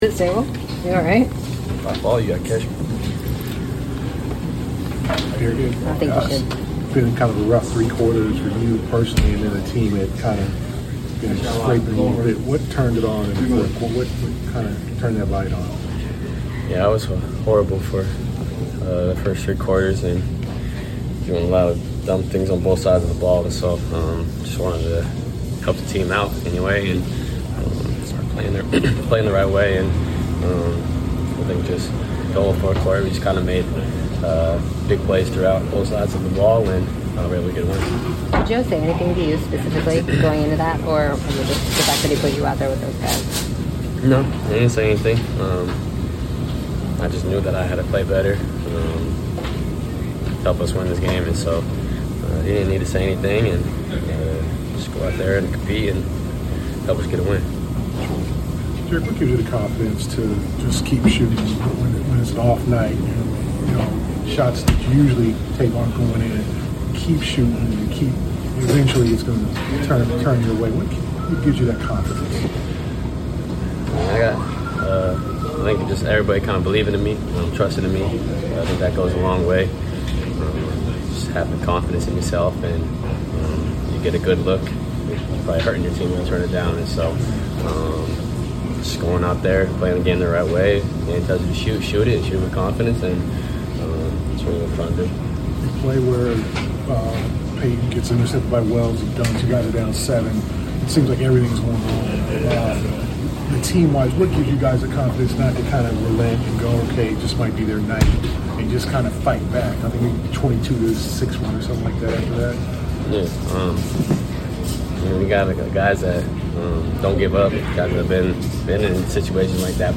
03-20-26 Boston Celtics Guard Derrick White Postgame Interview